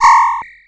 RetroGamesSoundFX / Alert / Alert03.wav
Alert03.wav